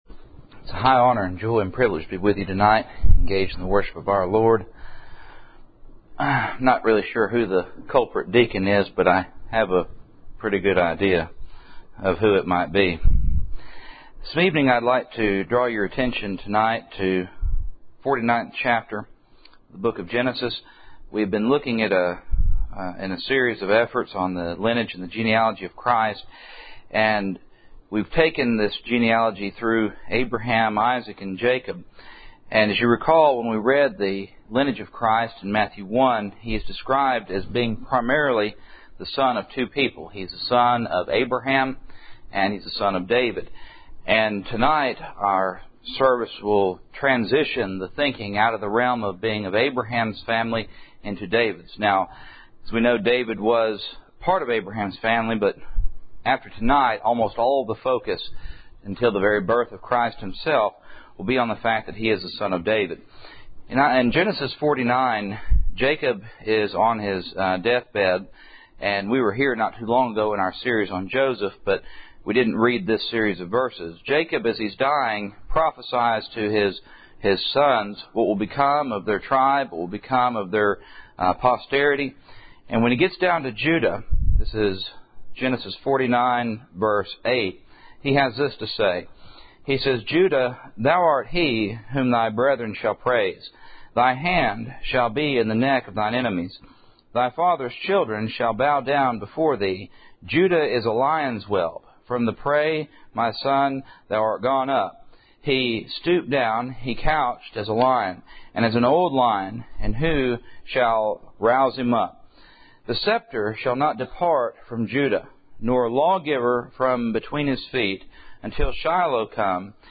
The Lineage and Genealogy of Christ Service Type: Cool Springs PBC Sunday Evening %todo_render% « Marriage